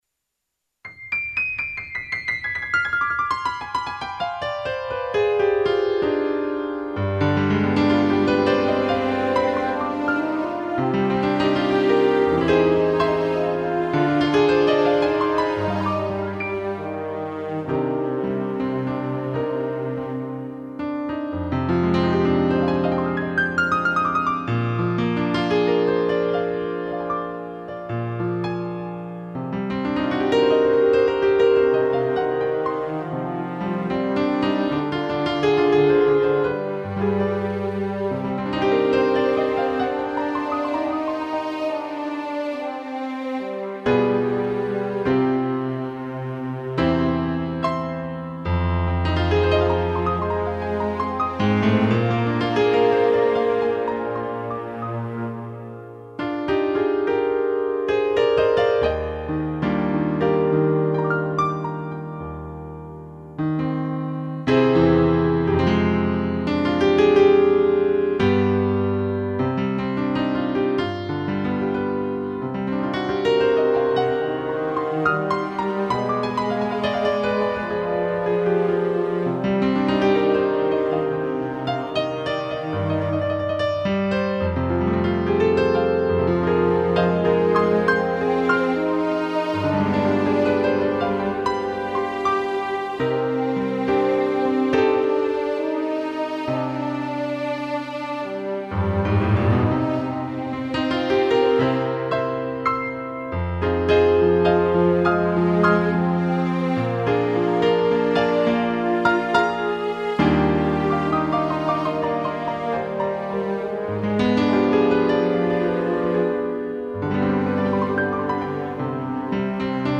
piano e tutti
(instrumental)